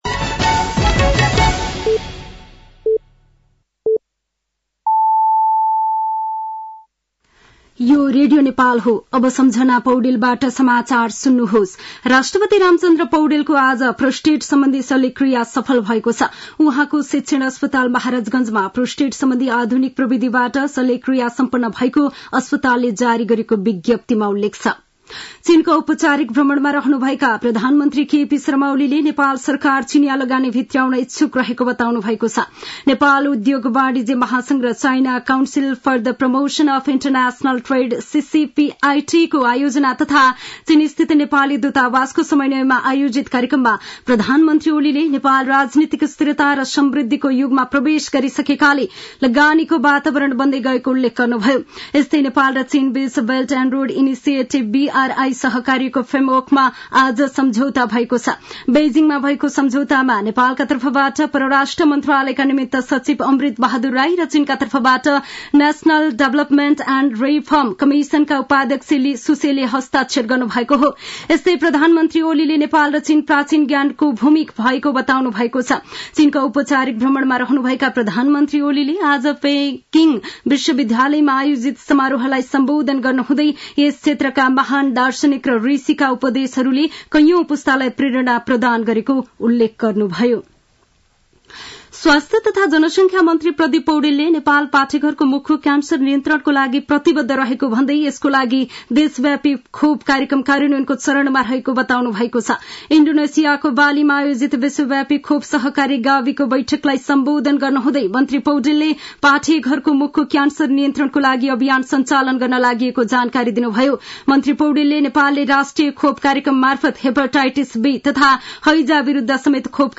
साँझ ५ बजेको नेपाली समाचार : २० मंसिर , २०८१
5-pm-nepali-news-8-19.mp3